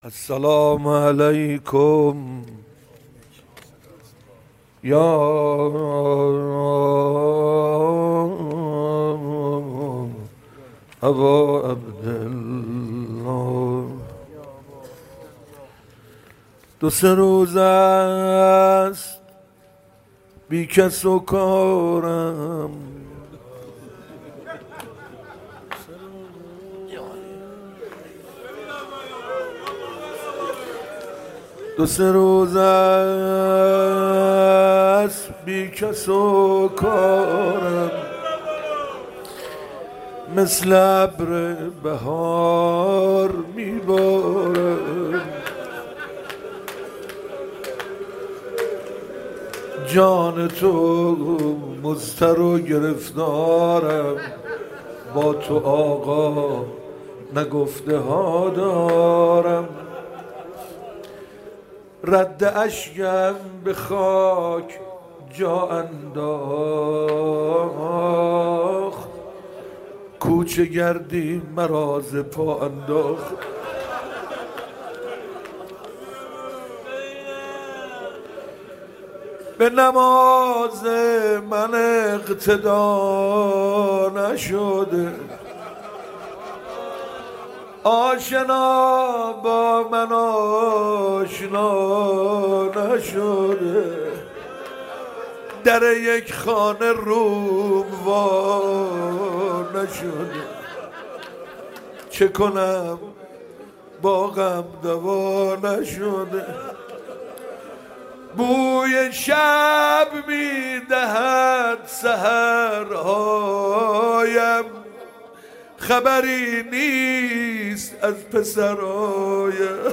مناجات و قرائت دعای روز عرفه